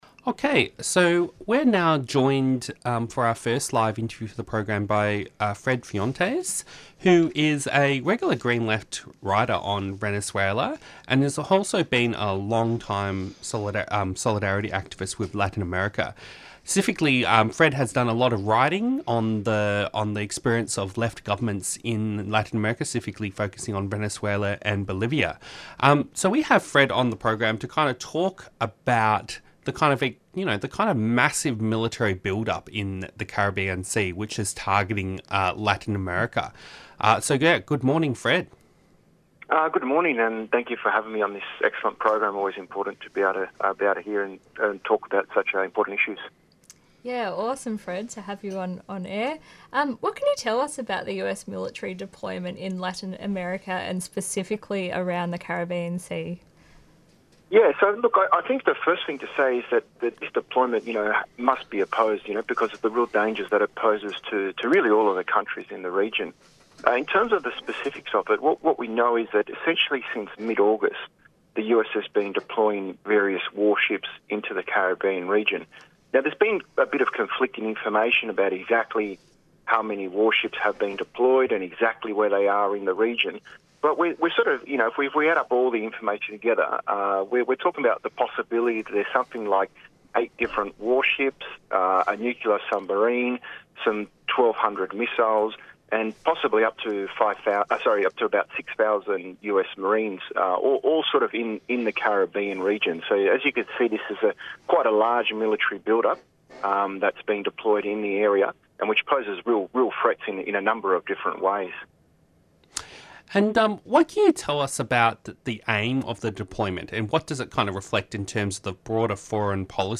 Newsreports Presenters discuss the following news reports and updates in local and international politics.
Interviews and Discussion